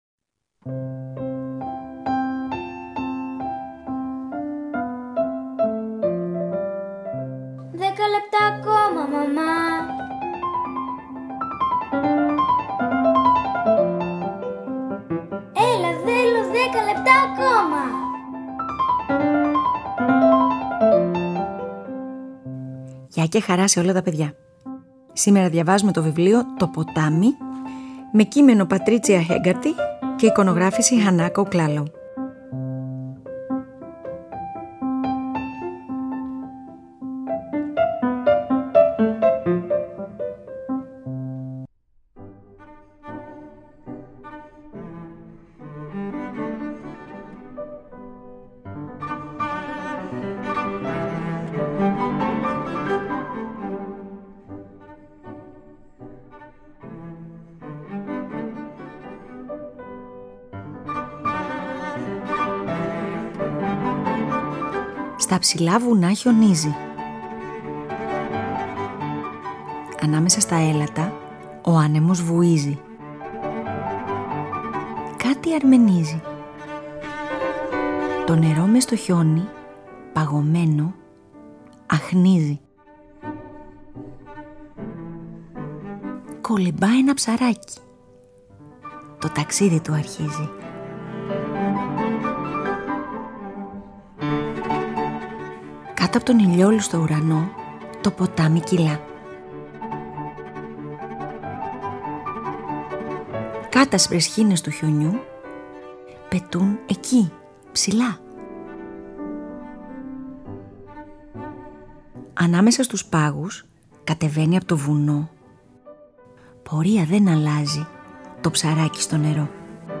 Antonin Dvorak, Sonatina for violin and piano in G Major, Op.100, B.120 – III Scherzo